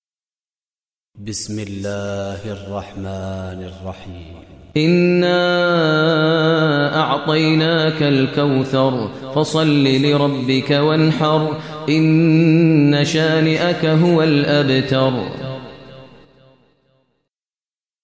Surah Kausar Recitation Maher al Mueaqly
Surah Kausar, listen online mp3 tilawat / recitation in Arabic in the voice of Imam e Kaaba Sheikh Maher al Mueaqly.